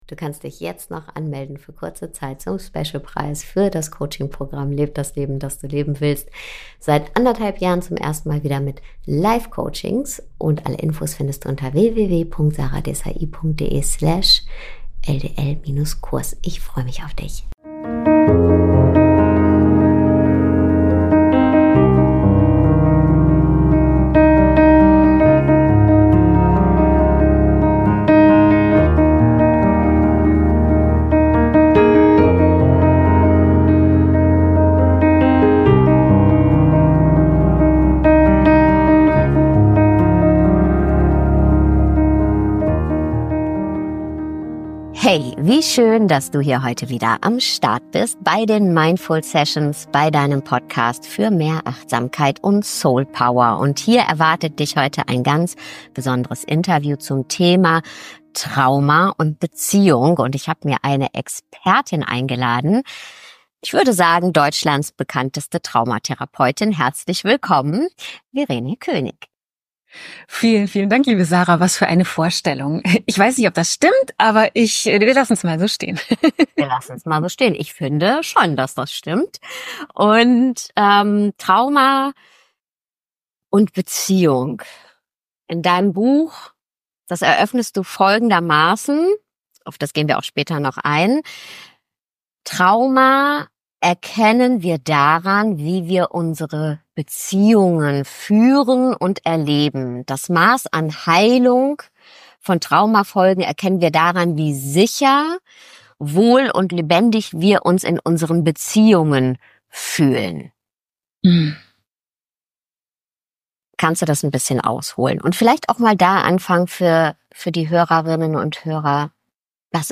Trauma und Beziehungen - Interview